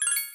MenuHit.ogg